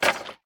small_destroy4.ogg